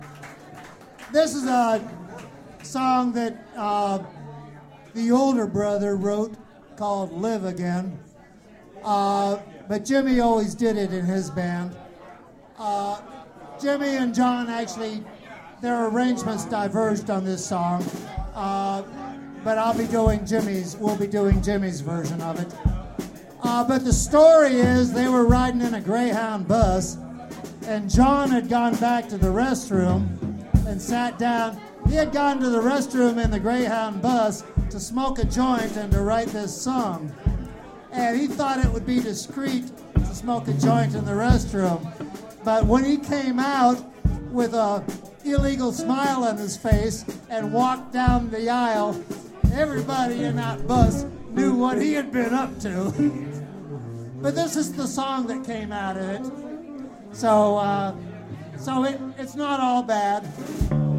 - Memorial concert at Bird's -
These links are to the recording of the show from my Tascam DR-07 that was mounted right in front of the monitor.